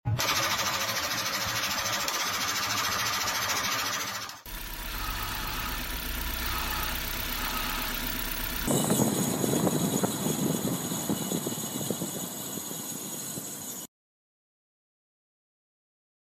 Most Popular Car Issues in UK 🇬🇧 (w/ Sounds)